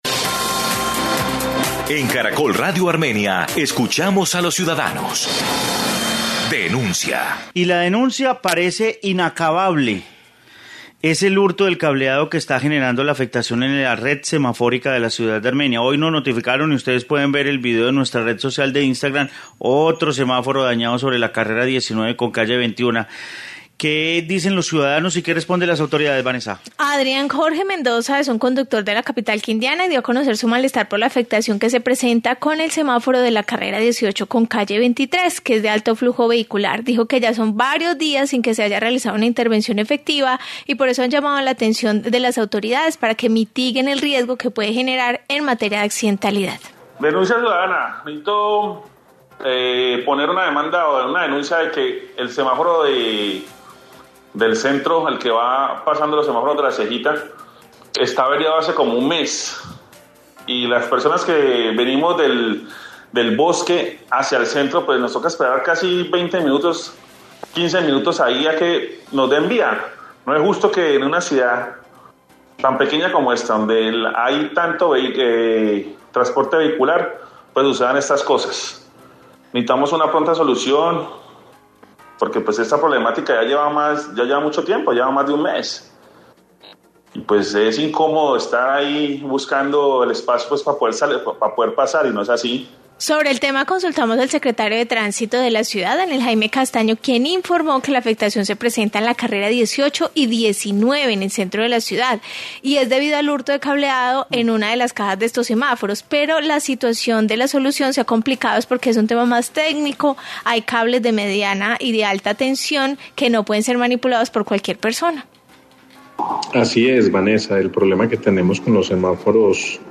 Informe semáforos de Armenia